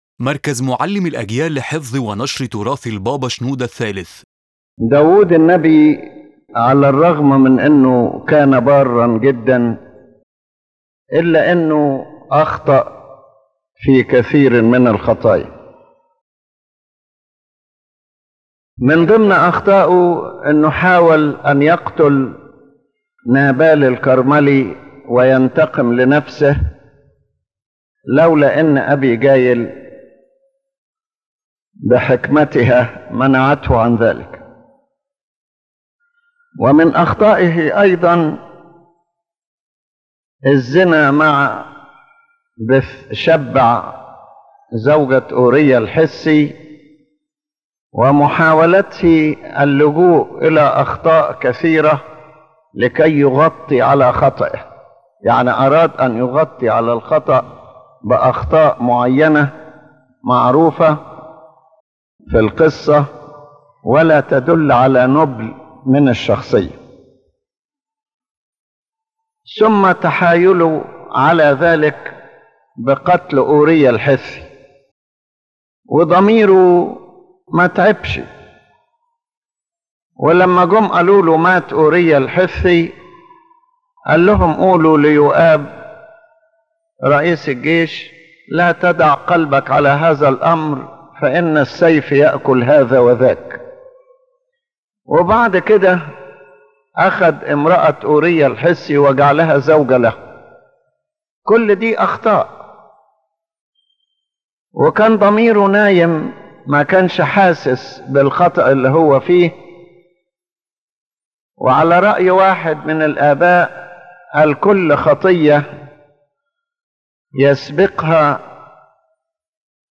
His Holiness Pope Shenouda III spoke about David the Prophet, showing that though he was very righteous, he was not free from sin.